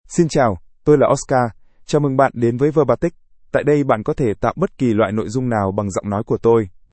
OscarMale Vietnamese AI voice
Oscar is a male AI voice for Vietnamese (Vietnam).
Voice sample
Male
Oscar delivers clear pronunciation with authentic Vietnam Vietnamese intonation, making your content sound professionally produced.